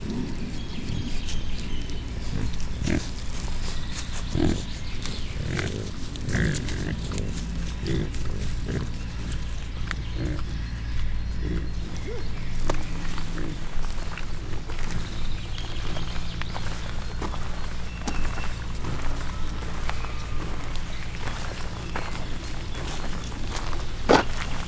Directory Listing of /_MP3/allathangok/miskolcizoo2018_professzionalis/orvos_pekari/
kovetjukapekarikat_miskolczoo0024.WAV